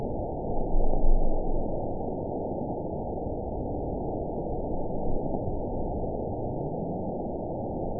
event 920142 date 02/29/24 time 00:43:08 GMT (1 year, 9 months ago) score 9.19 location TSS-AB05 detected by nrw target species NRW annotations +NRW Spectrogram: Frequency (kHz) vs. Time (s) audio not available .wav